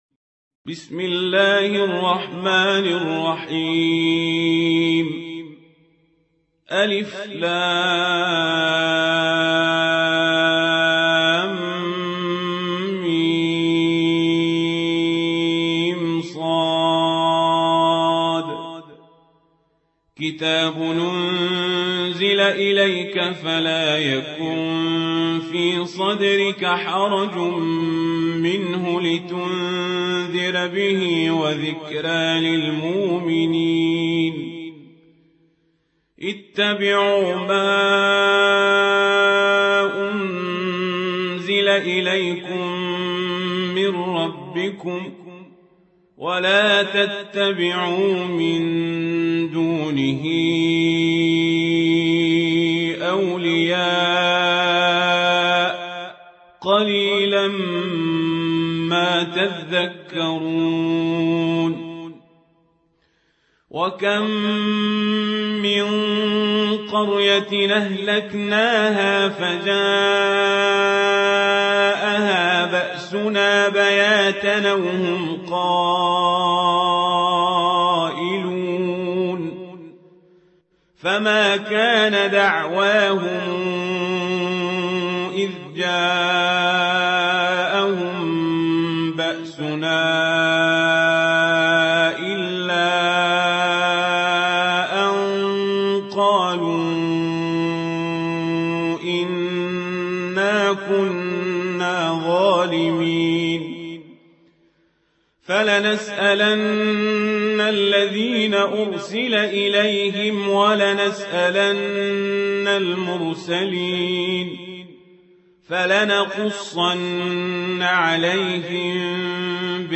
تحميل : 7. سورة الأعراف / القارئ القزابري / القرآن الكريم / موقع يا حسين